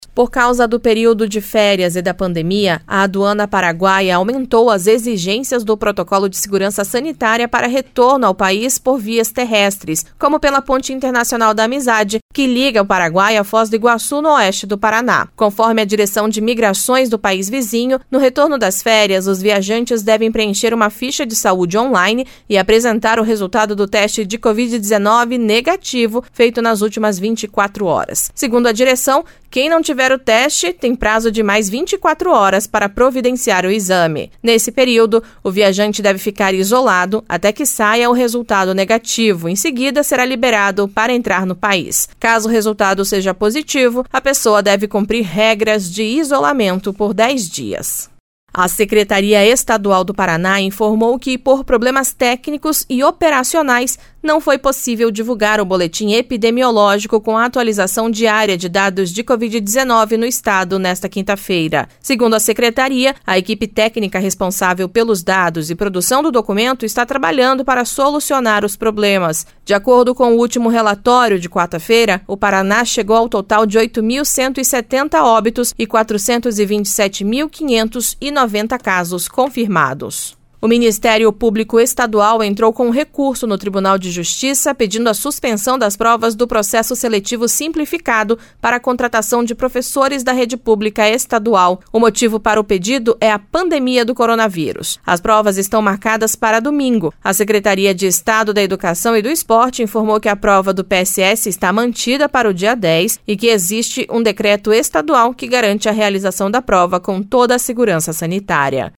Giro de Notícias Manhã SEM TRILHA